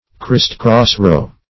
Christcross-row \Christ"cross-row`\,